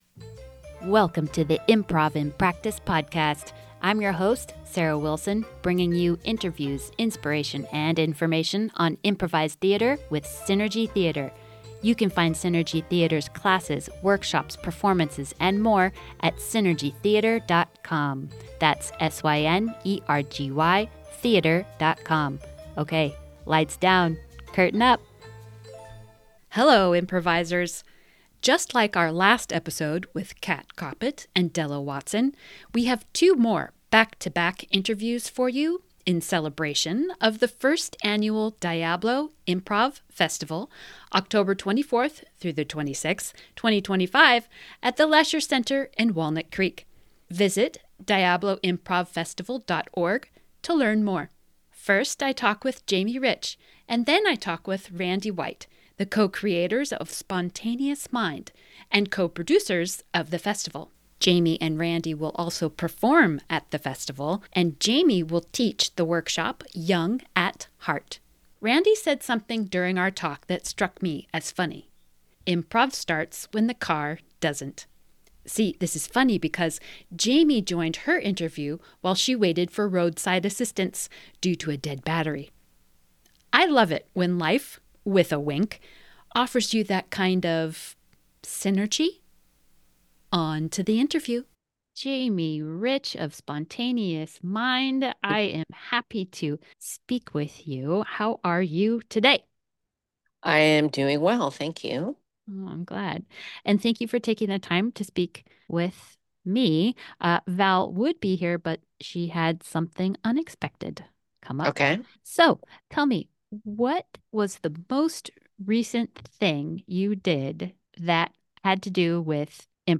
We have another round of back-to-back interviews for you in this episode: